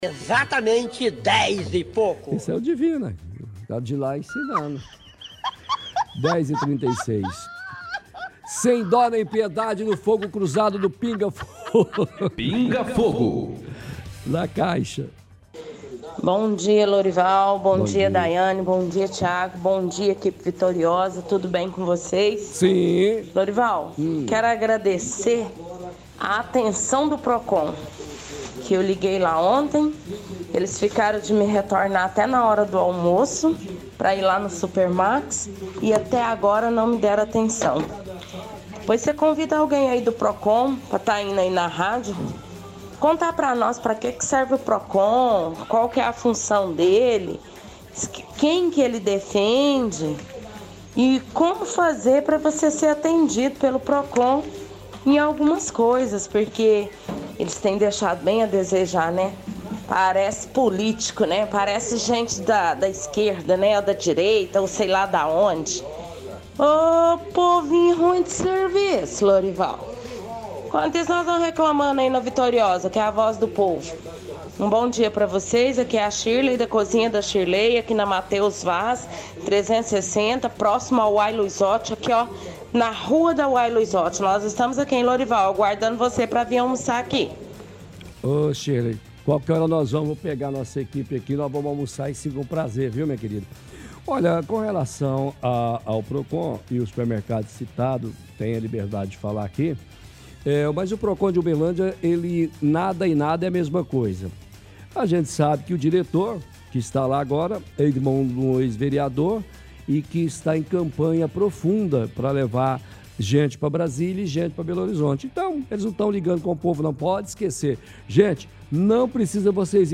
– Ouvinte agradece ironicamente ao Procon, que havia ficado de dar uma resposta sobre reclamação feita a respeito de supermercado, mas nada fez.